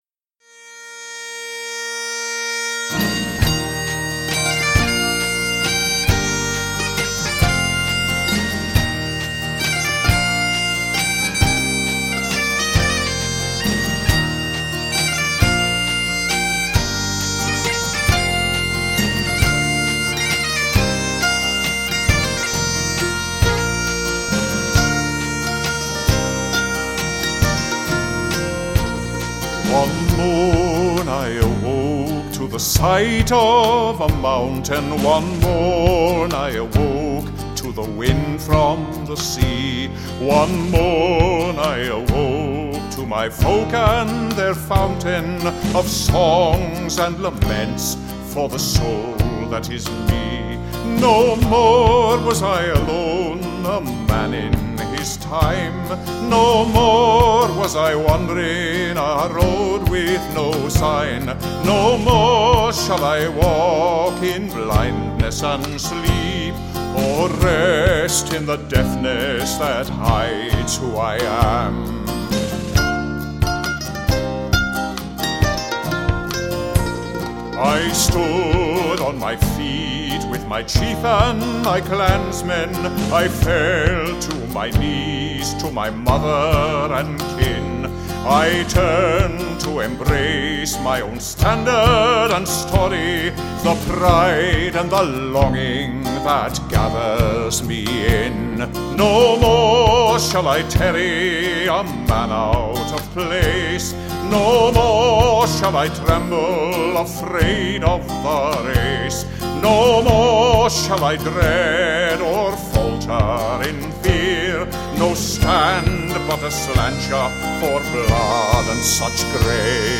Vocals
Bagpipe